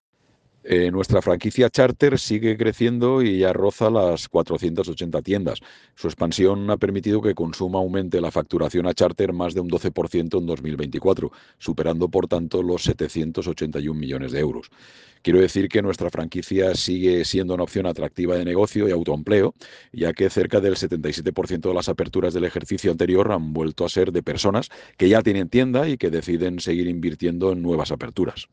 Corte de voz